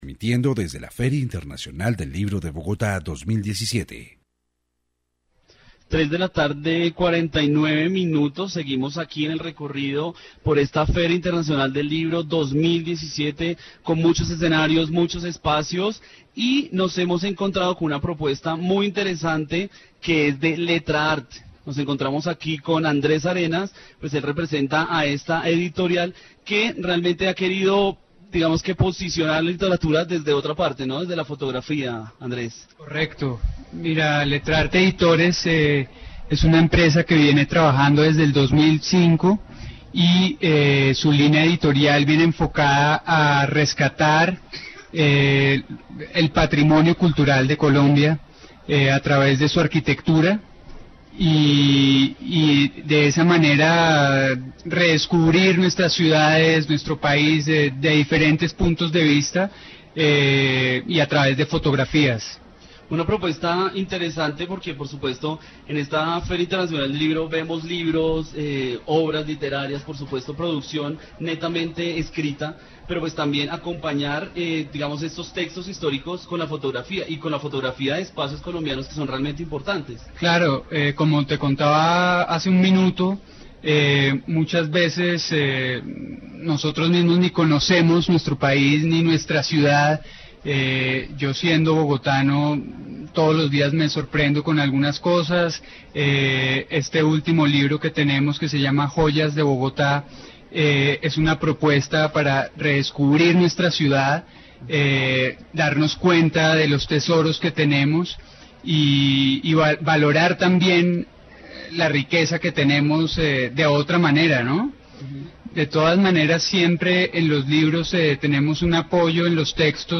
Feria del Libro 2017. Informe radial
Programas de radio